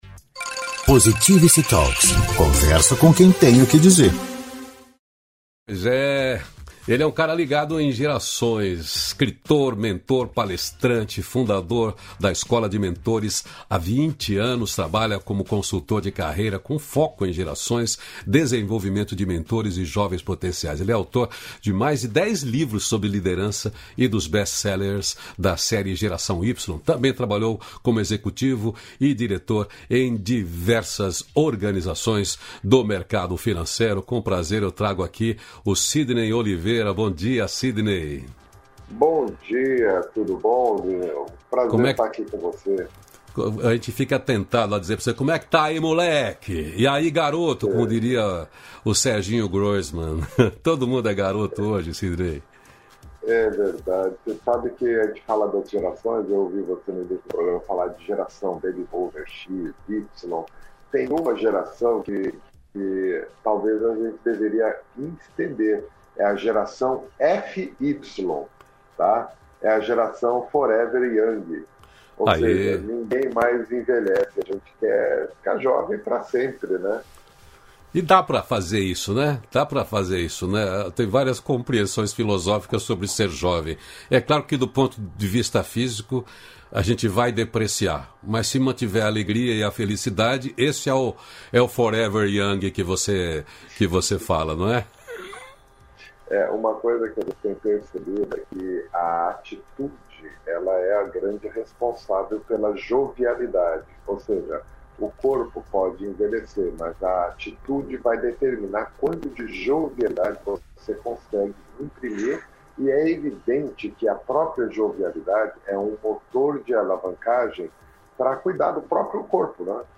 289-feliz-dia-novo-entrevista.mp3